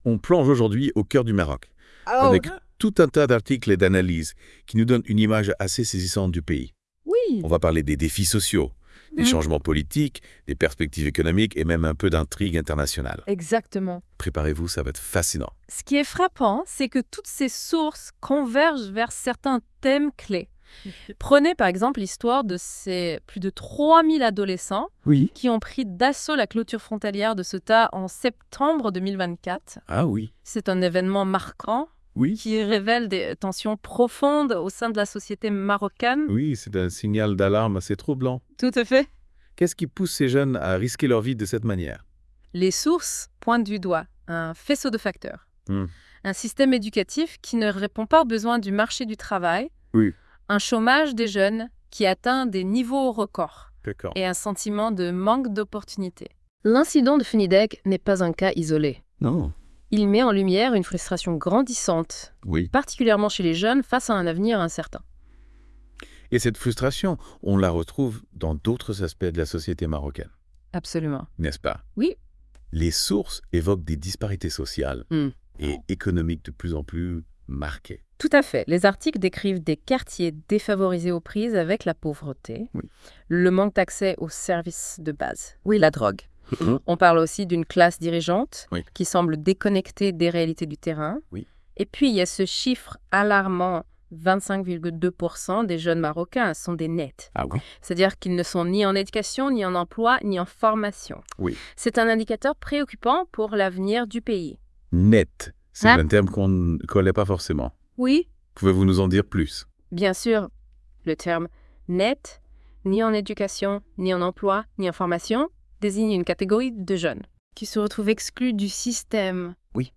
+ Débat - Podcast : Les chroniqueurs de la Web Radio R212 ont lus attentivement cet "I-Chroniques Retro 2024" de L'ODJ Média et ils en ont débattu dans ce podcast I-Chroniques - Retro 2024.wav (40.94 Mo) I-Chroniques Retro 2024 répond aux questions suivantes : Politique et Gouvernance : 1. Le récent remaniement ministériel est-il un véritable souffle de renouveau ou un simple ajustement technique ? 2.